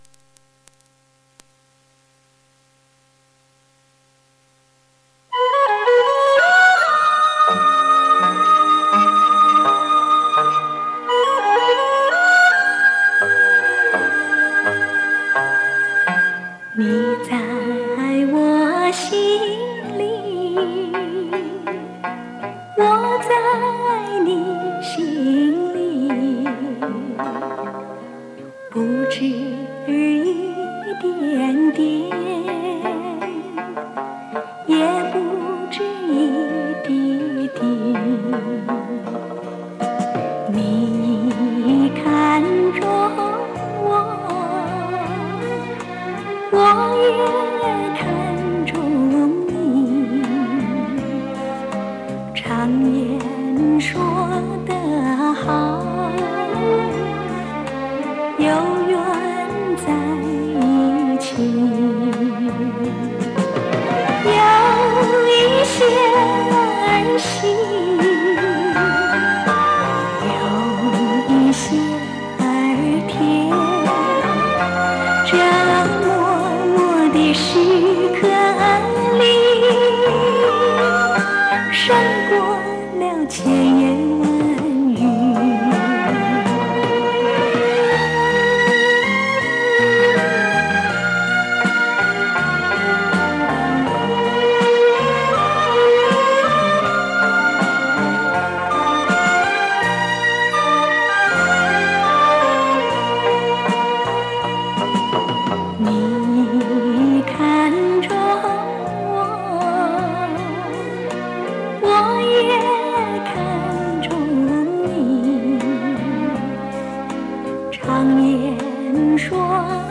磁带数字化：2022-06-03